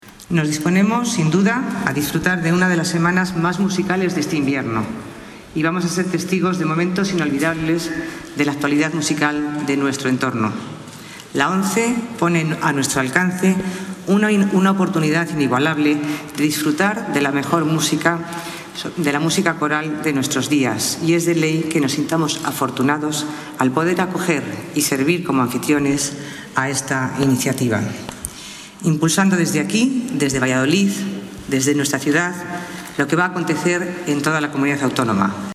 La importante cita cultural comenzaba minutos antes del comienzo del concierto de apertura, con la recepción de bienvenida ofrecida en el Ayuntamiento de Valladolid, el mismo 5 de marzo, por la concejala de Cultura, Comercio y Turismo, Mercedes Cantalapiedra, a los participantes de la Bienal, y en la que animó al público castellano y leonés a "disfrutar de una de las semanas más musicales de este invierno.